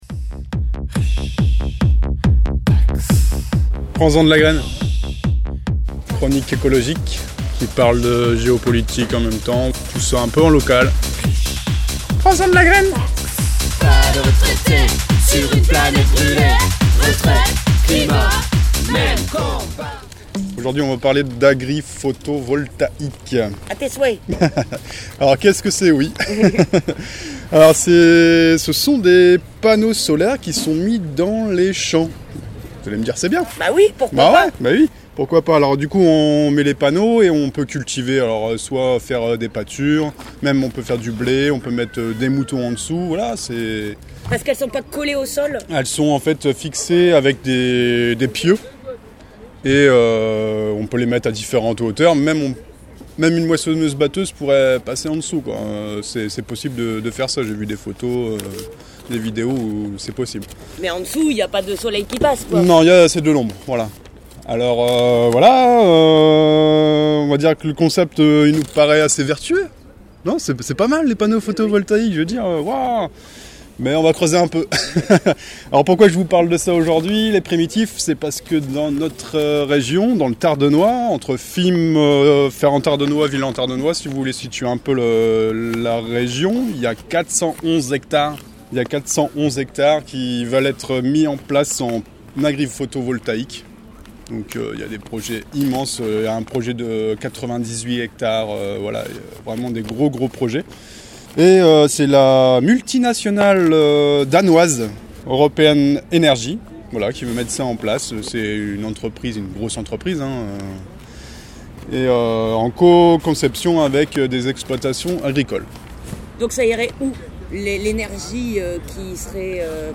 Chronique de novembre (9:29)